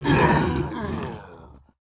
Index of /App/sound/monster/orc_general
dead_2.wav